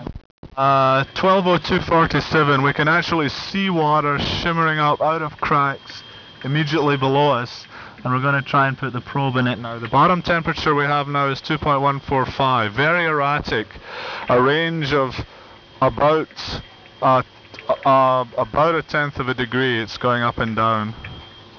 From inside Alvin